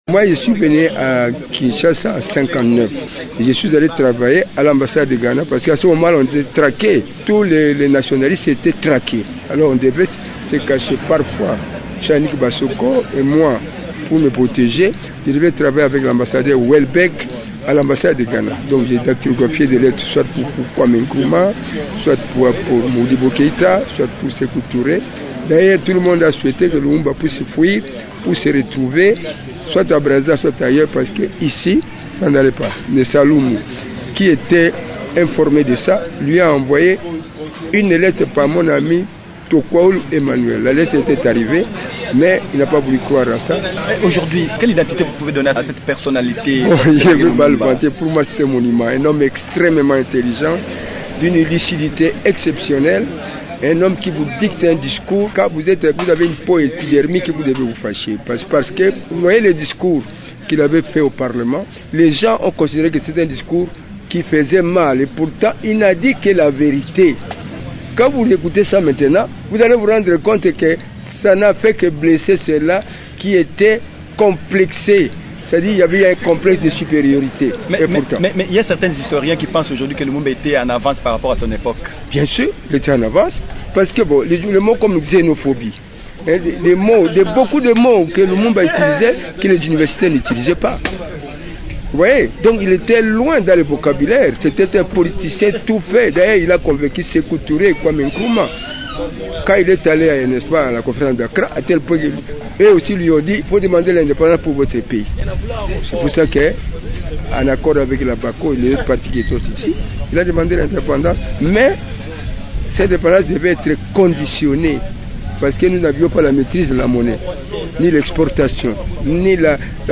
Rappel des faits au micro